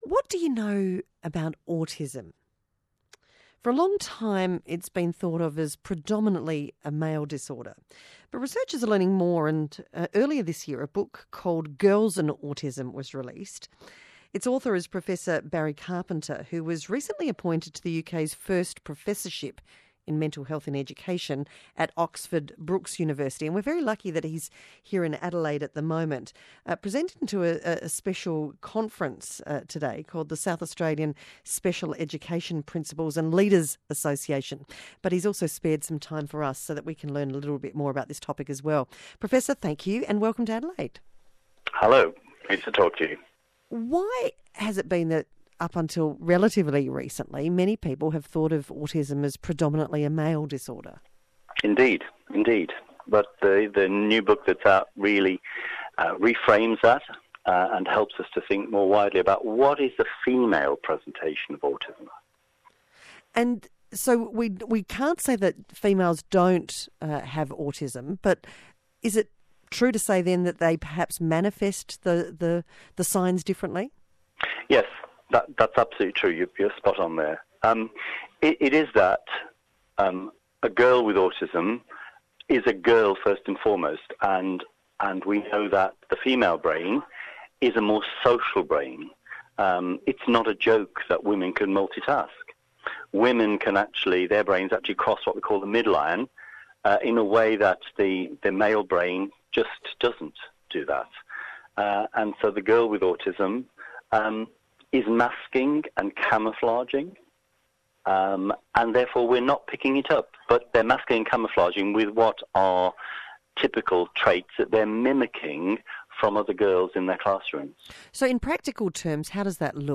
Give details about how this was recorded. First broadcast, Wednesday 26th June, Adelaide, Australia